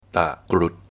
ตะกรุด (dtà-grùts)
ตะกรุด (dtà-grùt) / 用泰语发音，请点击此处的播放按钮。 / Pronounced in Thai. Please press play the button here.